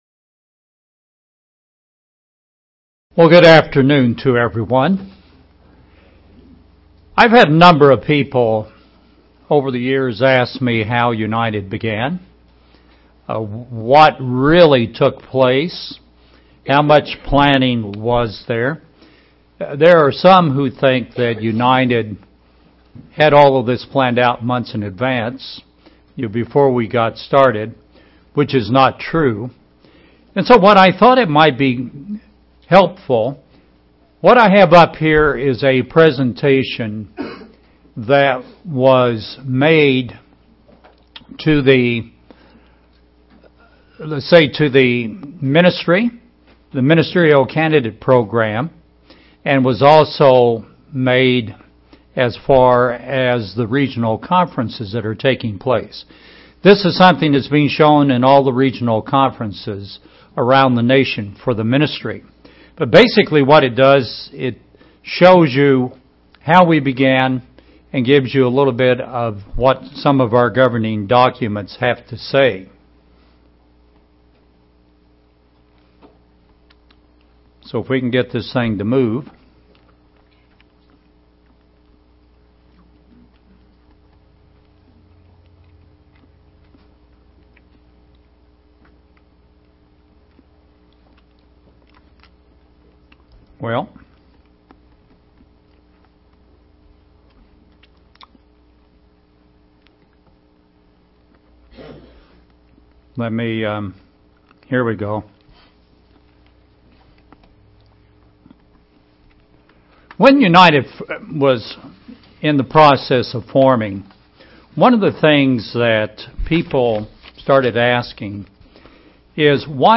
This sermon gives detail on how the United Church of God began.